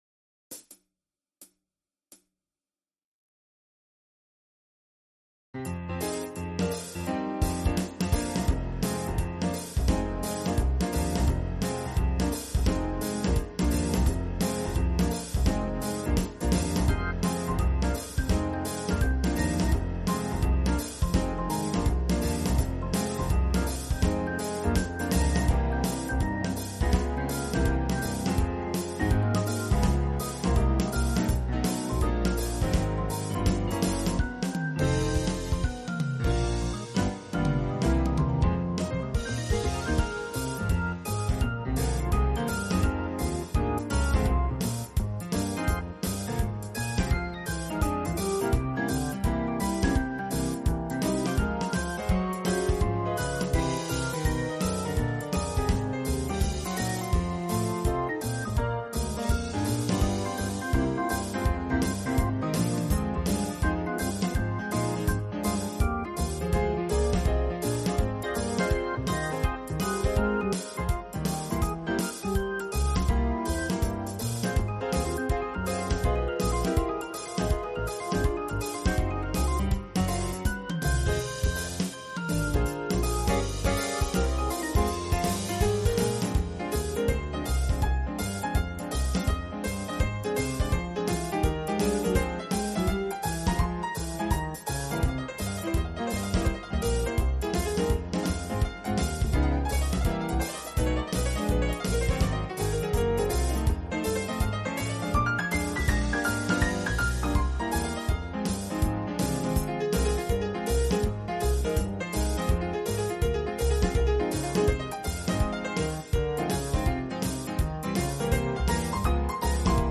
versão instrumental multipista